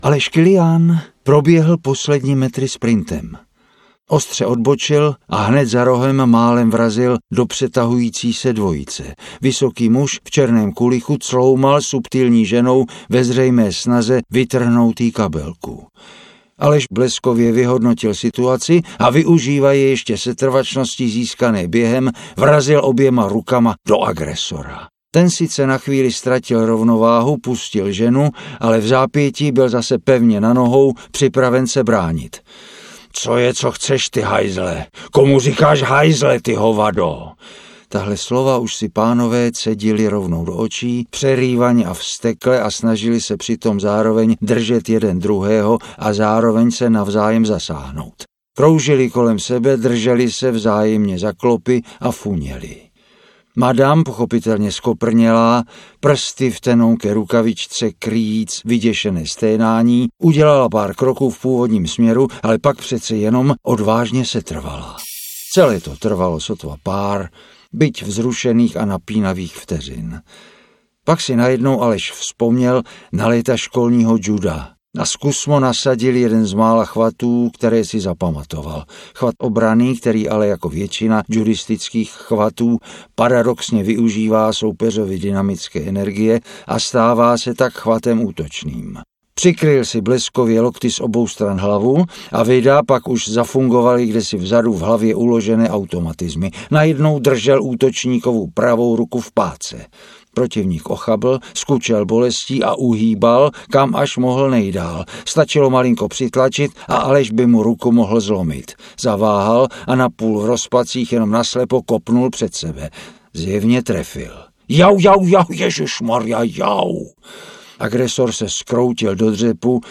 Vítězná sestava audiokniha
Ukázka z knihy